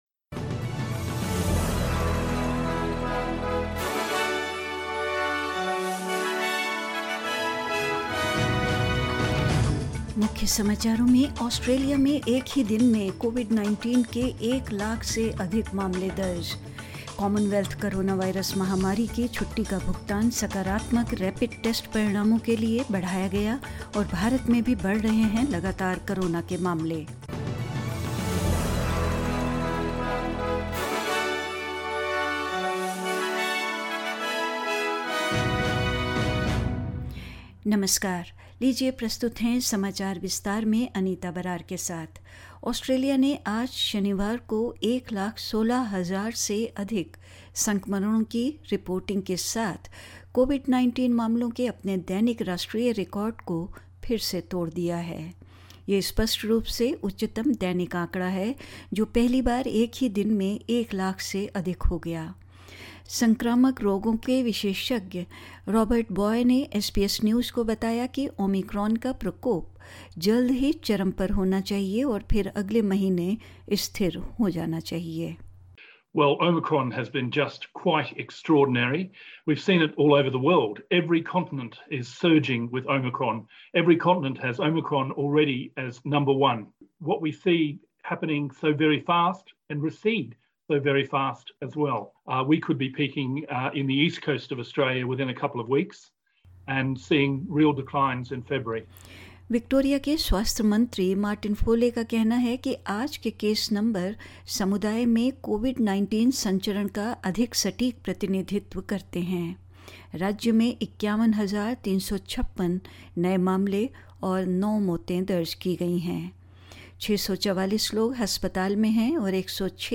In this latest SBS Hindi news bulletin: Australia records more than 116,000 cases of COVID-19 in a single day; Comonwealth Coronavirus Pandemic Leave payments extended to recognise positive rapid test results; New official modeling shows the NSW's hospitalisations of COVID-19 patients are up 3.2 per cent and more news.